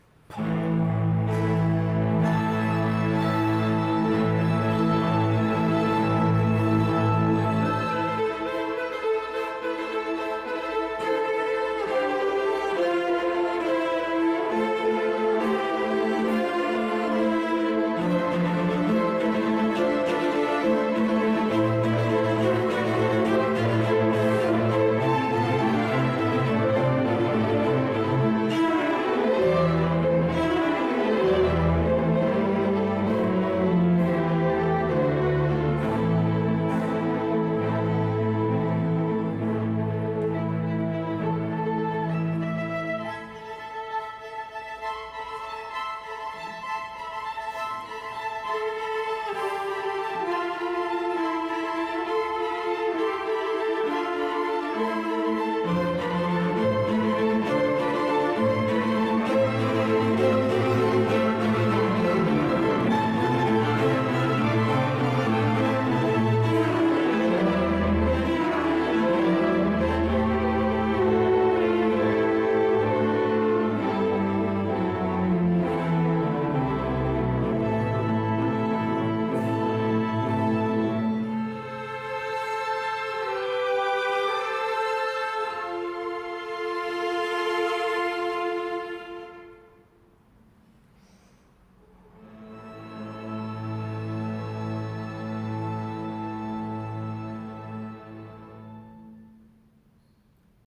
Tweet Type: String Orchestra Tags